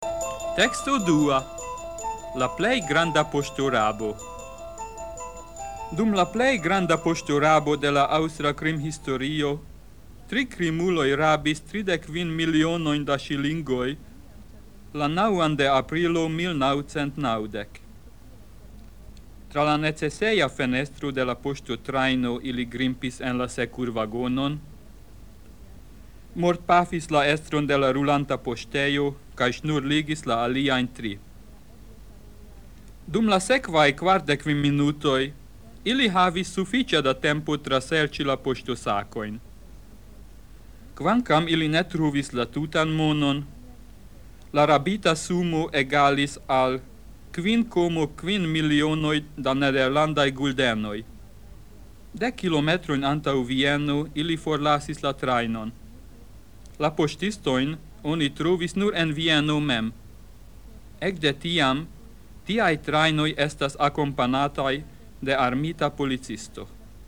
Temo: Sonmaterialo en E-o kun komprenekzercoj.